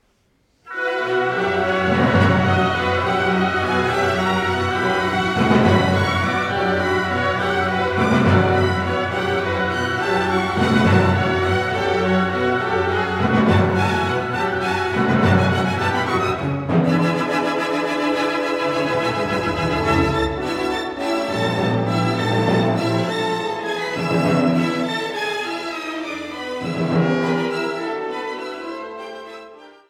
repräsentative Live-Aufnahmen
Tempo giusto – Adagio